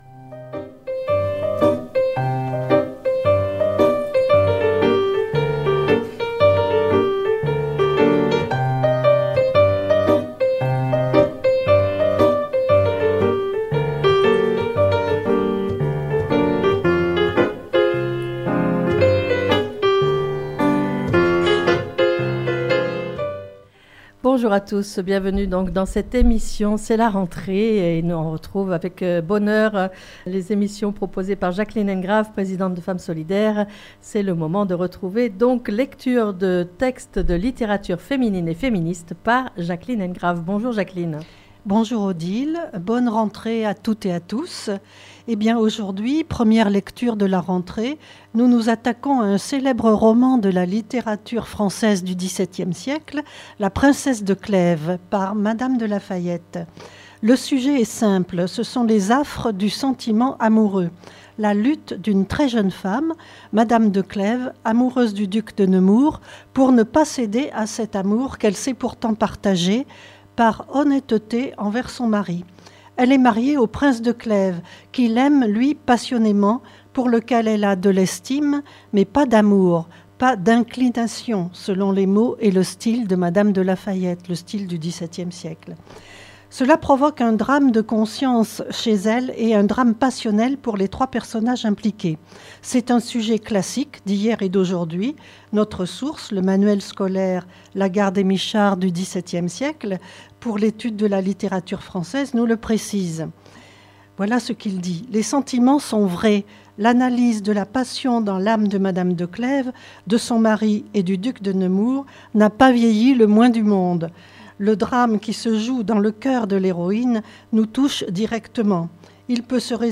7 - Lecture de texte de littérature féminine et féministe - Mme De La Fayette.mp3 (9.01 Mo)